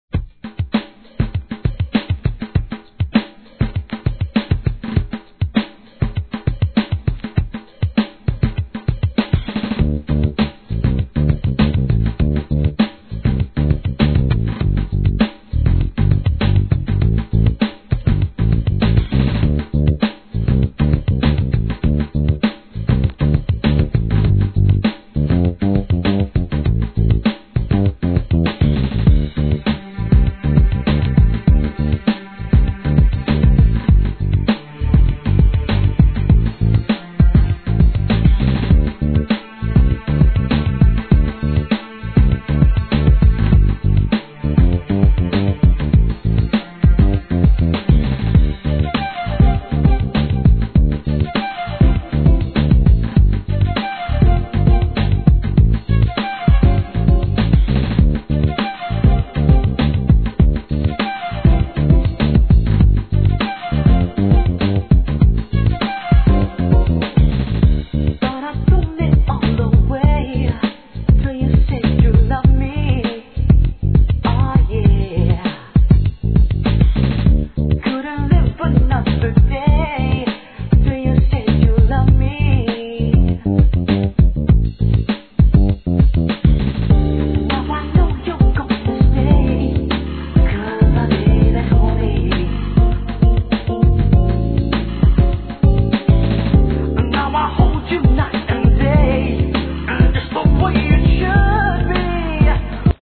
HIP HOP/R&B
1992年、温かみを感じさせるミディアムRHYTHMでの洒落オツ、カナダ産マイナーR&B!